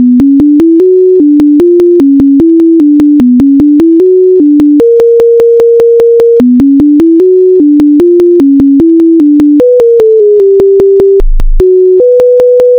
Channels: 1 (mono)